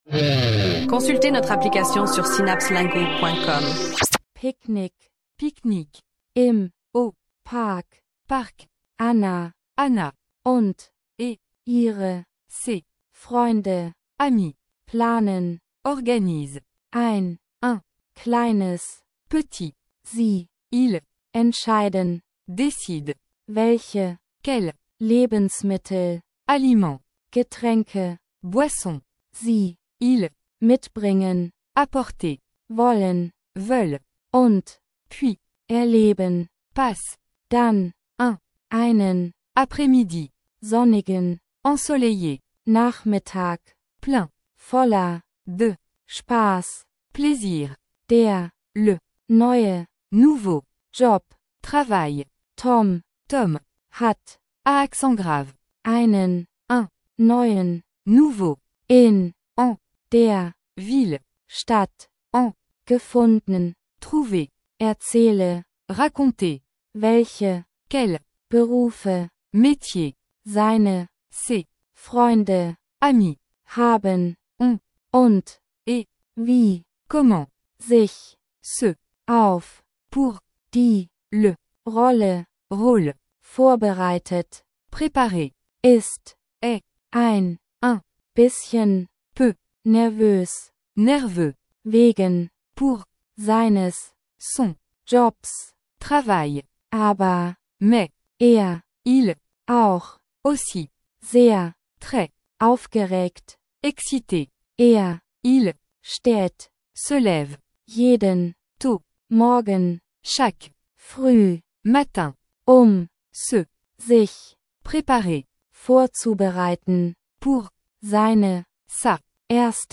Plongez dans un monde passionnant d’apprentissage des langues avec des textes d’apprentissage divertissants et des chansons entraînantes dans différentes combinaisons de langues.
Écoutez, apprenez et profitez de mélodies accrocheuses qui vous aideront à maîtriser la langue de manière ludique.